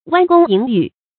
彎弓飲羽 注音： ㄨㄢ ㄍㄨㄙ ㄧㄣˇ ㄧㄩˇ 讀音讀法： 意思解釋： 形容勇猛善射。